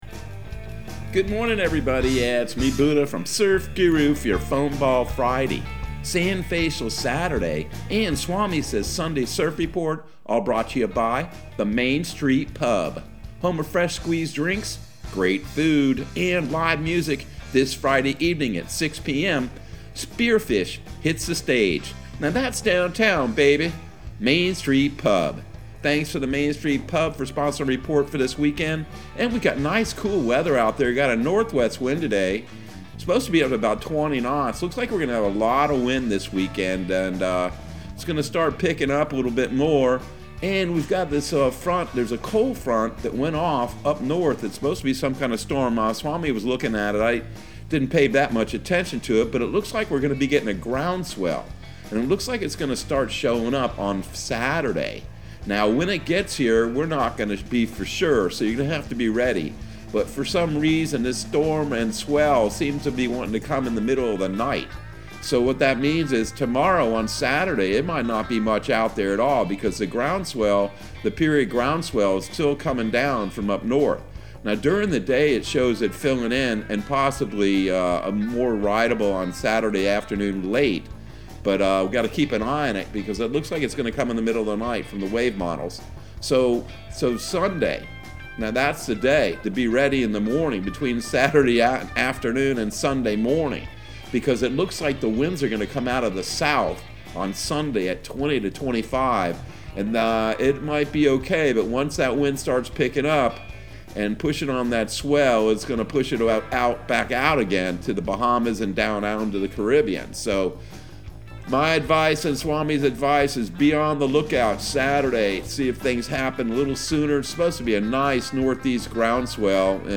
Surf Guru Surf Report and Forecast 01/14/2022 Audio surf report and surf forecast on January 14 for Central Florida and the Southeast.